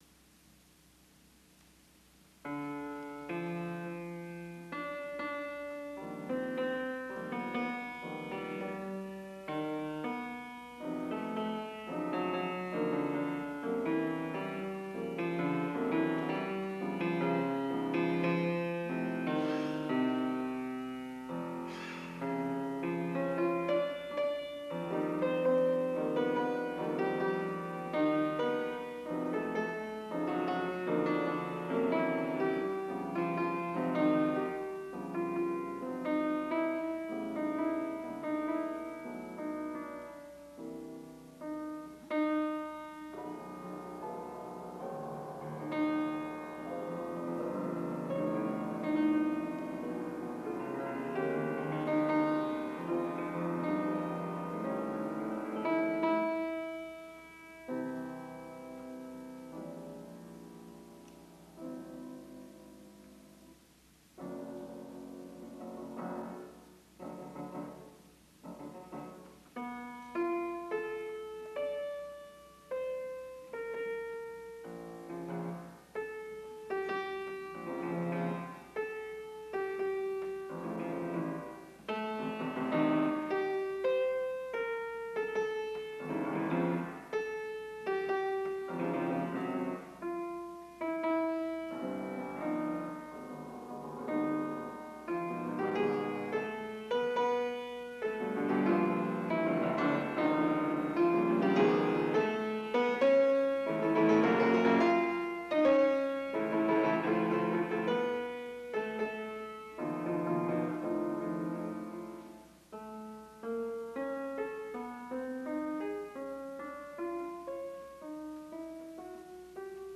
Elegien at Tully Hall